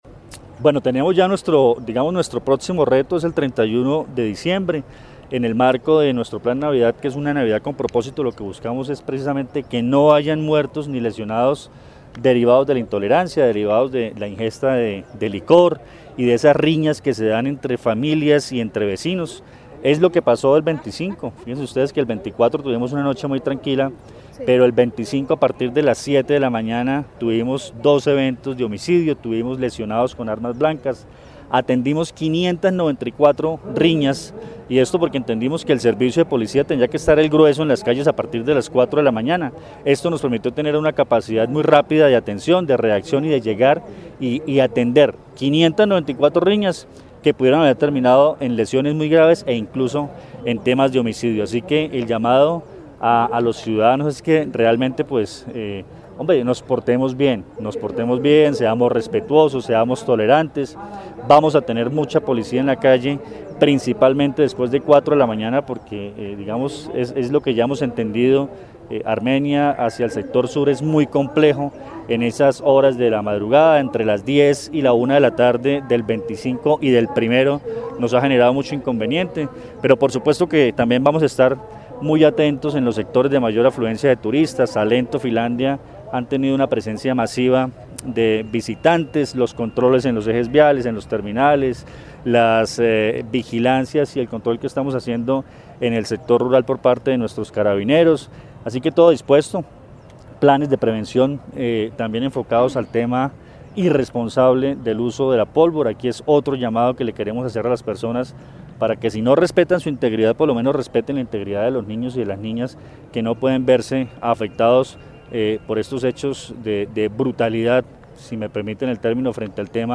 Coronel Luis Fernando Atuesta, comandante de la Policía del Quindío.